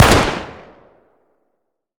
Index of /server/sound/weapons/cw_ar15
fire_longbarrel.wav